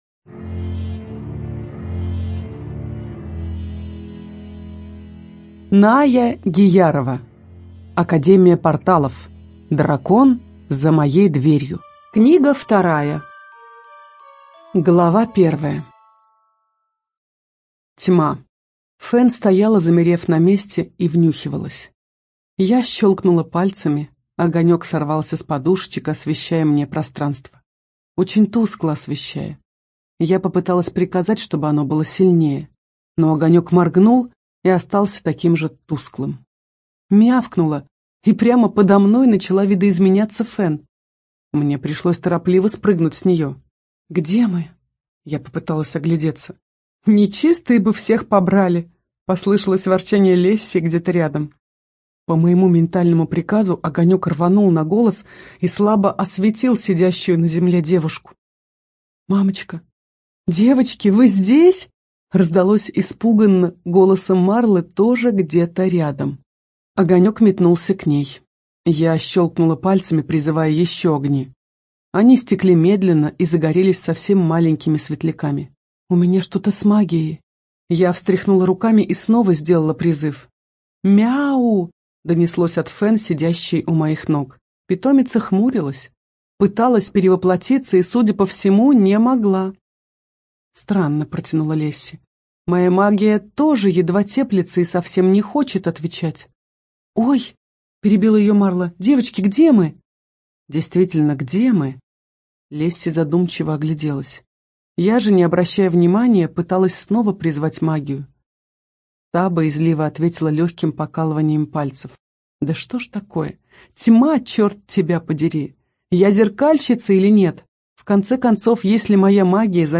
ЖанрЛюбовная проза, Фэнтези